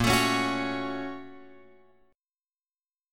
A# Minor Major 7th Sharp 5th